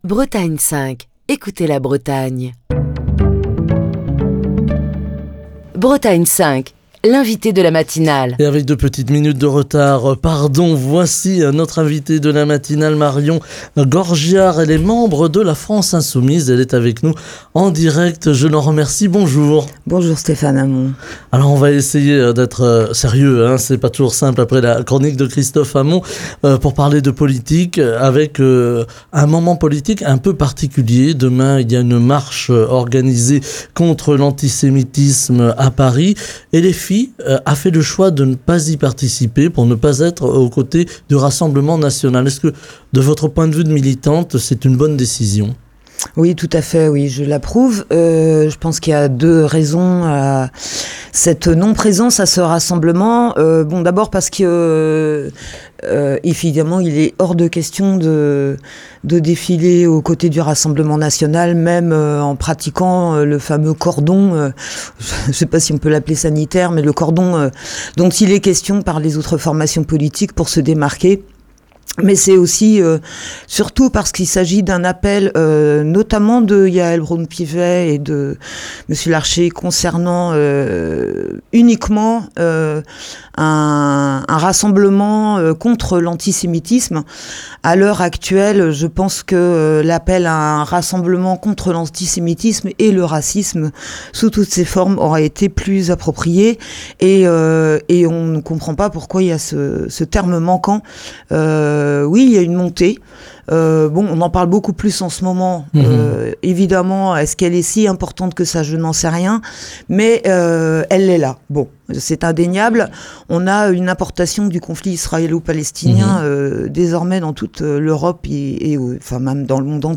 qui est l'invitée politique de Bretagne 5 Matin. Écouter Télécharger Partager le podcast Facebook Twitter Linkedin Mail L'invité de Bretagne 5 Matin